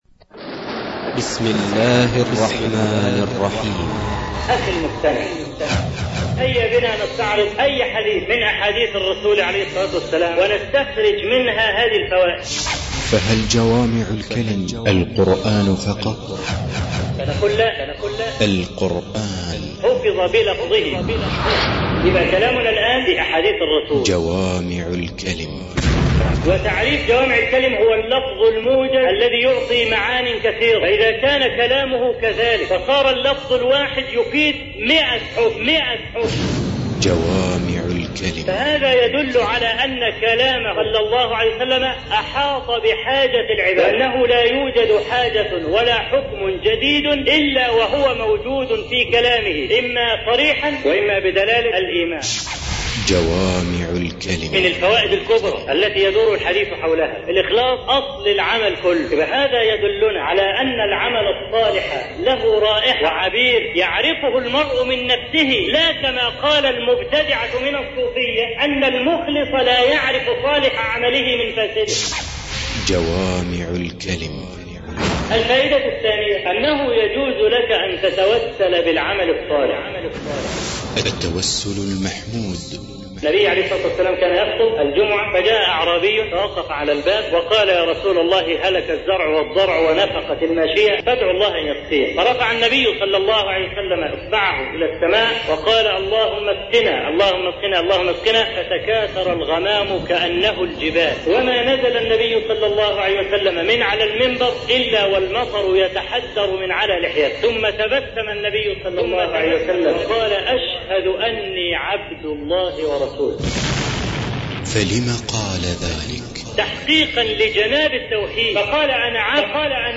السنة المطهرة خطب ومحاضرات المادة جوامع الكلم ...